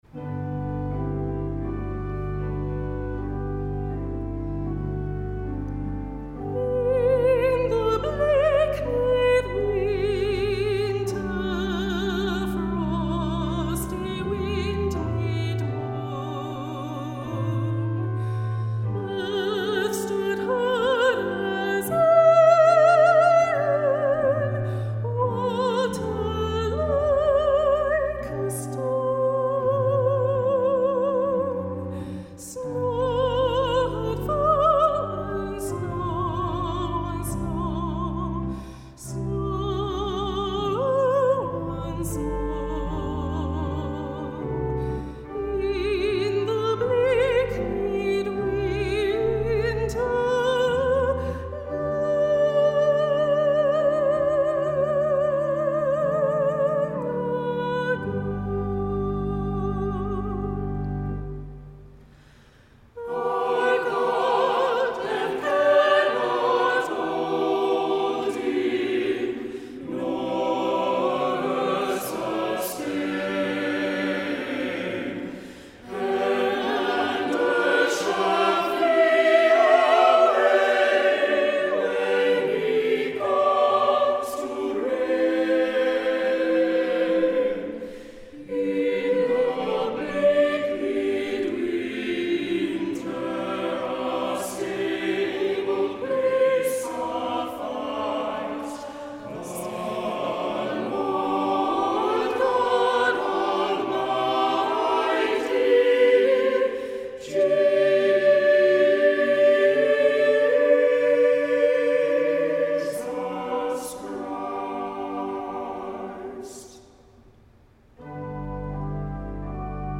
Voicing: "SATB","Soprano Solo","Tenor Solo"